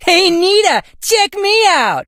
leon_lead_vo_02.ogg